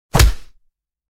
Epic Punch Sound Effect
Description: Epic punch sound effect. A powerful punch to the face or body in a cinematic style, often used in animations and video games. Sound of a hard punch to the face.
Epic-punch-sound-effect.mp3